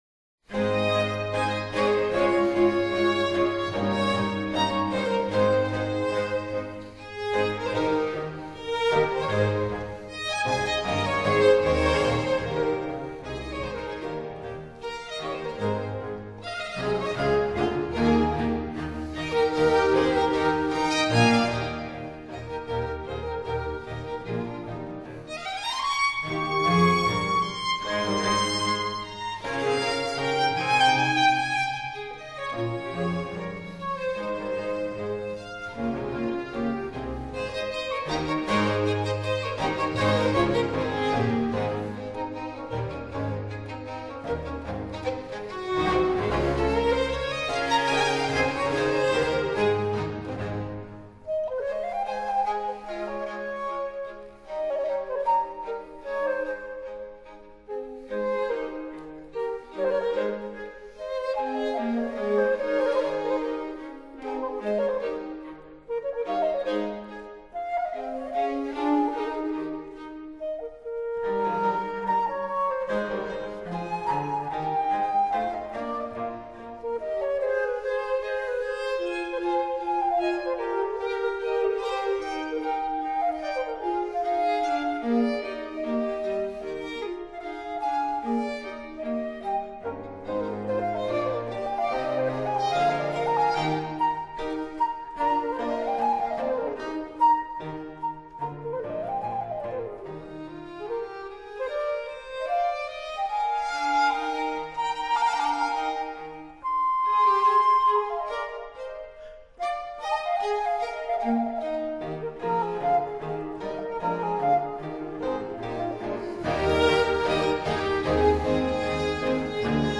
Allegretto